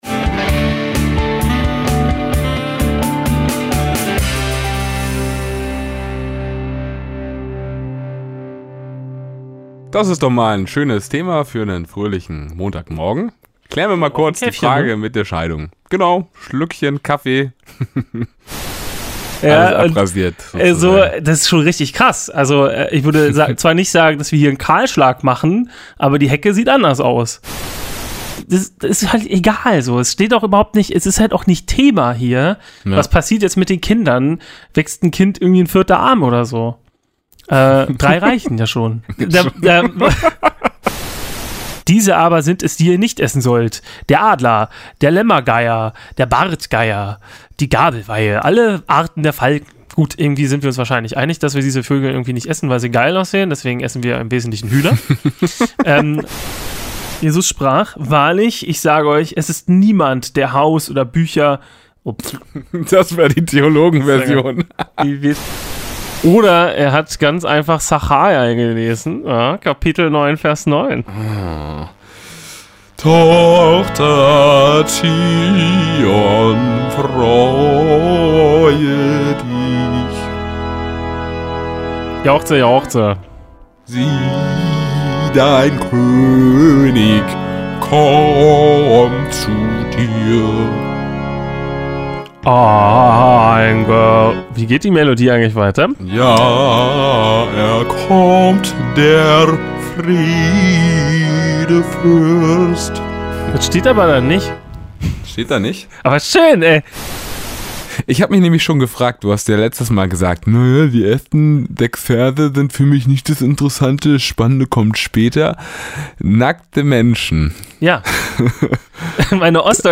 Outtakes